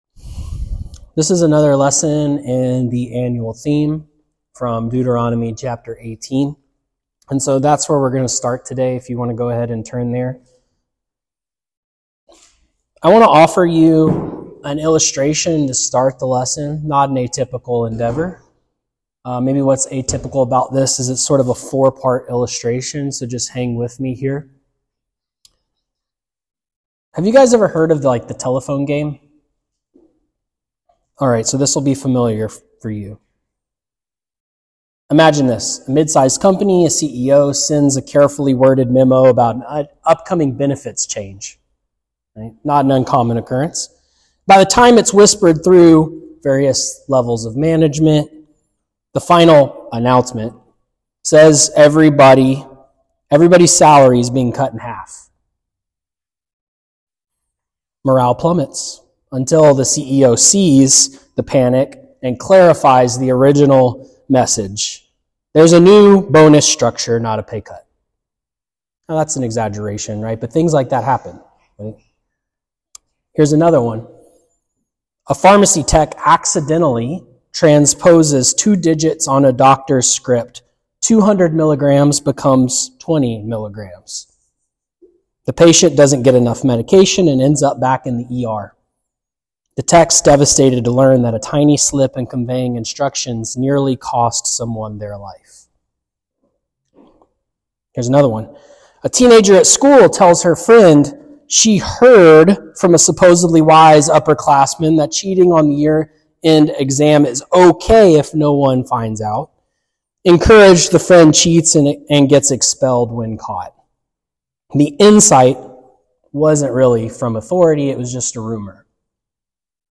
A lesson from our 2025 Annual theme sermon series that uses moments in the lives of God’s prophets to explore the meaning, fulfillment, and application of Deuteronomy 18.18.